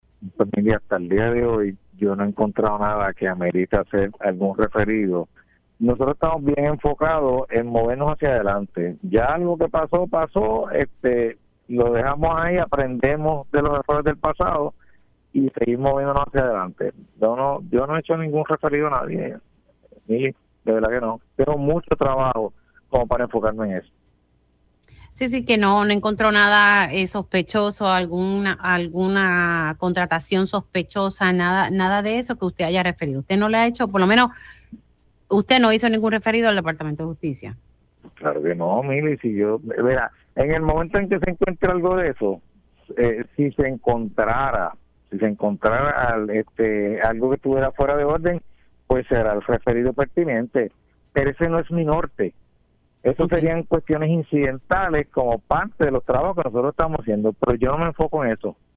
El secretario de Recursos Naturales y Ambientales, Waldemar Quiles rechazó en Pega’os en la Mañana que haya realizado un referido contra la exjefa de la agencia, Anaís Rodríguez, luego que el Departamento de Justicia citó a la licenciada para el próximo lunes, 23 de junio.